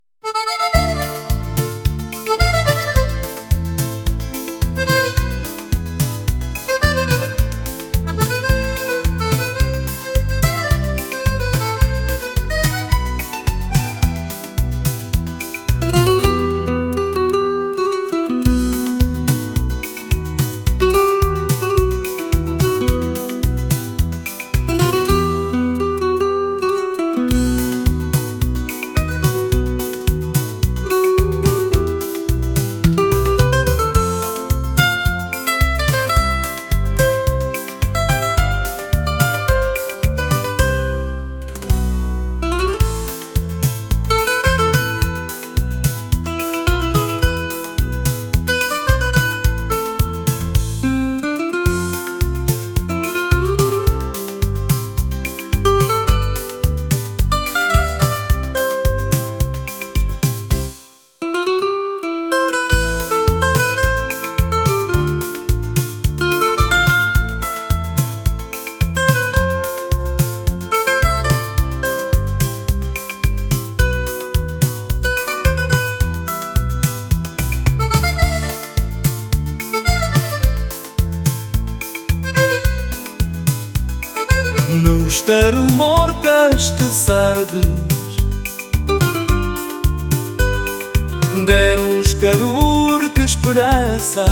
romantic | pop | rhythmic